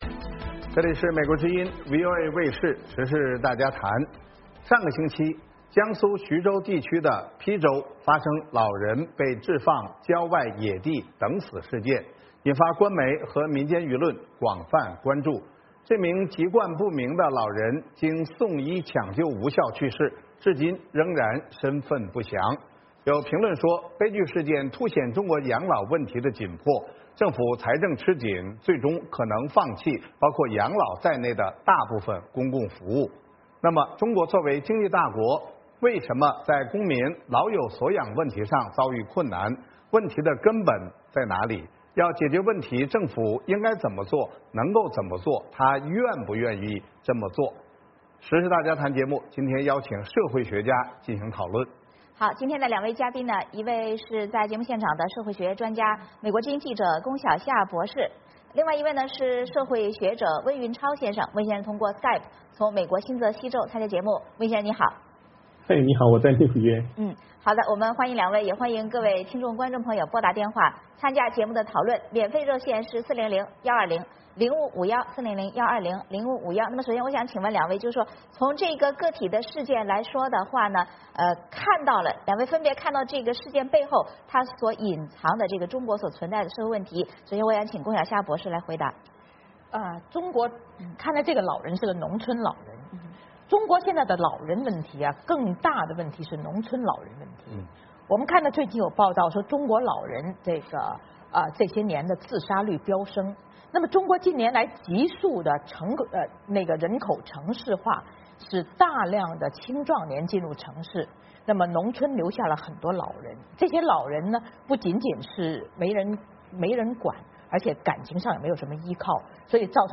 时事大家谈邀请社会学家进行讨论。